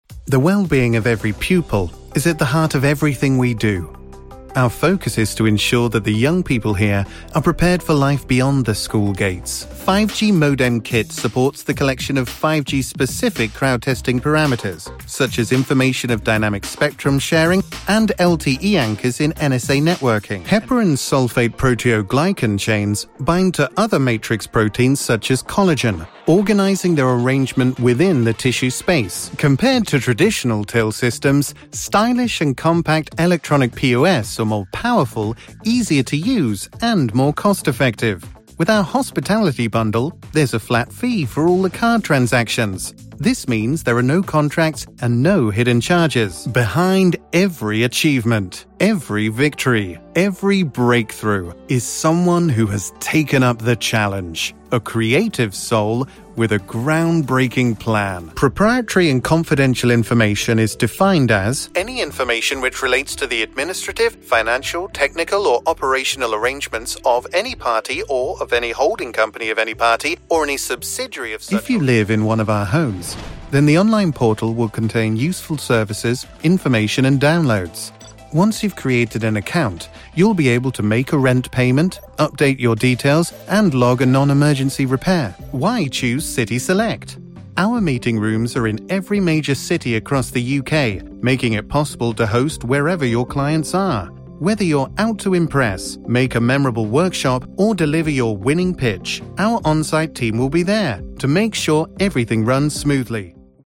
Hallo, ich bin ein britischer männlicher Sprecher mit einer warmen und vertrauenswürdigen Stimme, die sich für Unternehmenspräsentationen und E-Learning eignet, aber auch die Bandbreite hat, um an skurrilen Werbespots, Animationen und Videospielen zu arbeiten.
Sprechprobe: Industrie (Muttersprache):
Hi, I'm a male British voiceover with a warm and trustworthy voice suitable for corporate narration and elearning but with the range to also work on quirky commercials, animation, and video games.